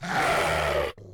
growl.wav